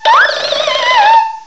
cry_not_phantump.aif